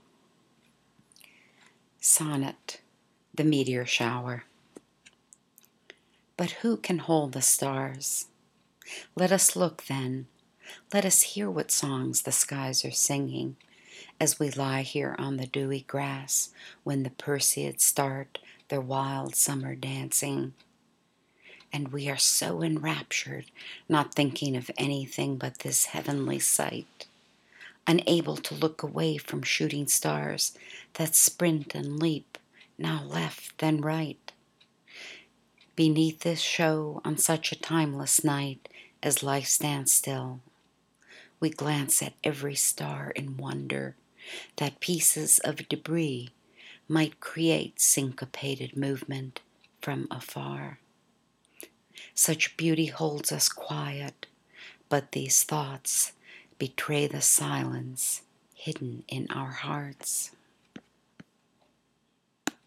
I’ve provided an audio clip below to listen to with the poem or while you look at the beautiful pictures above or the lovely one following the poem.